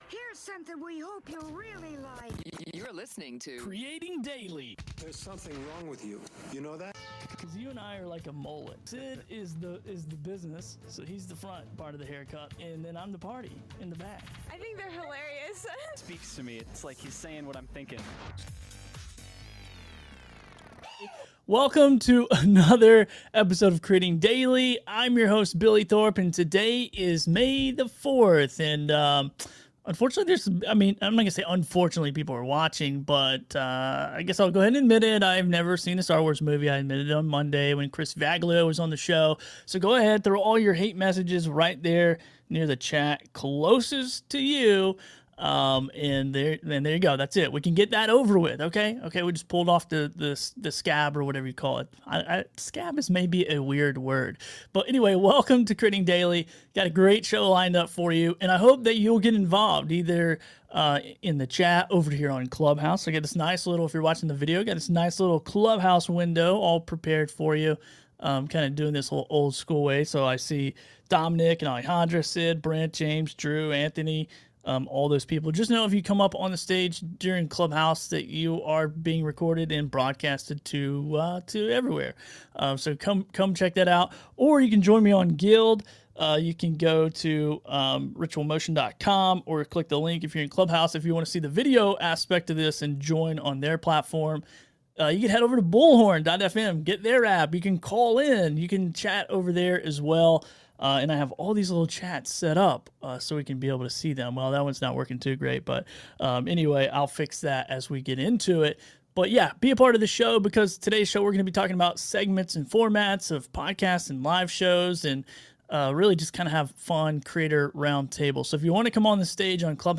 This is an open creator roundtable to discuss your podcasting and live stream formats and segments.